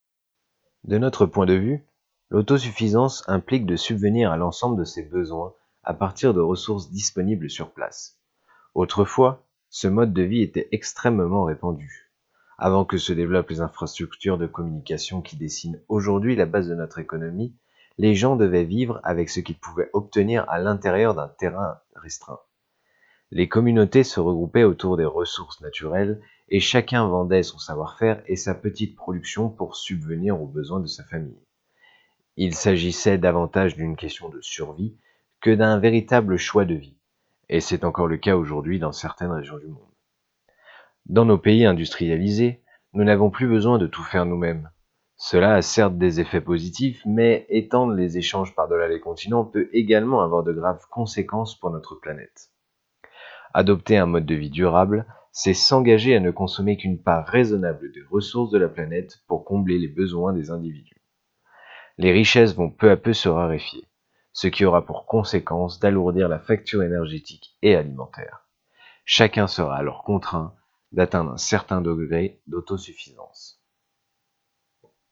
Extrait livre